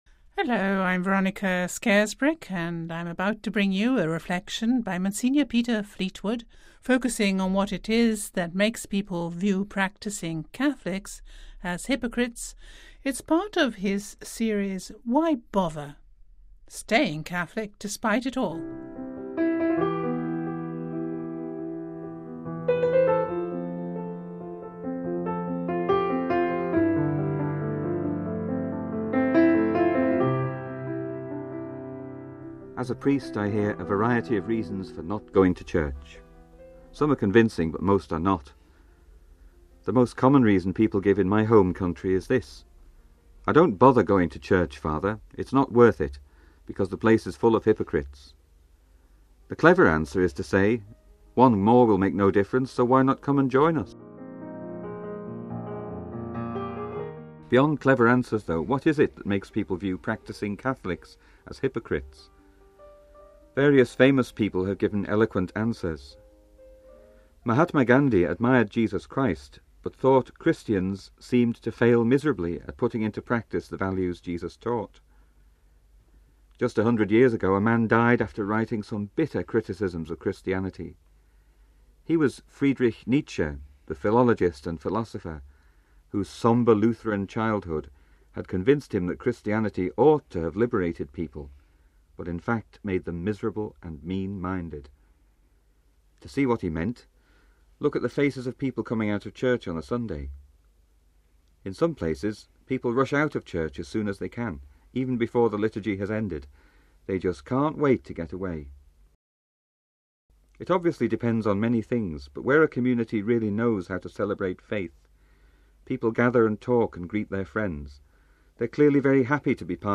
A reflection